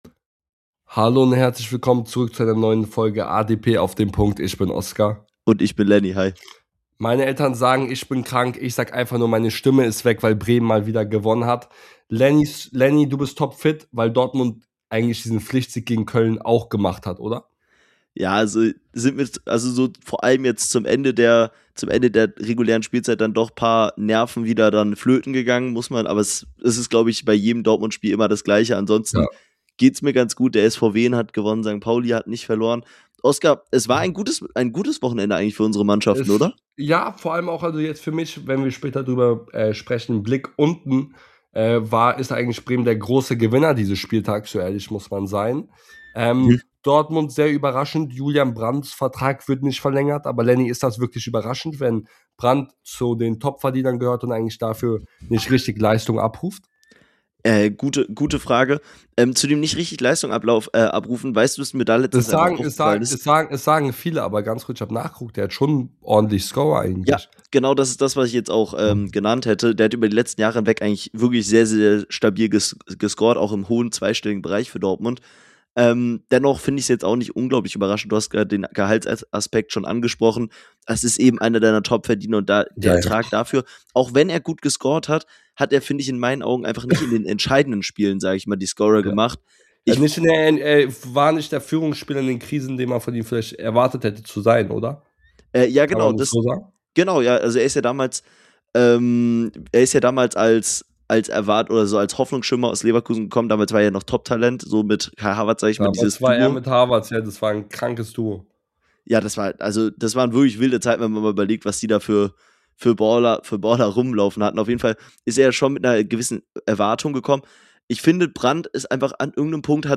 In der heutigen Folge reden die beiden Hosts über Kölns Krise , die Unruhe bei Wolfsburgs Niederlage und vieles mehr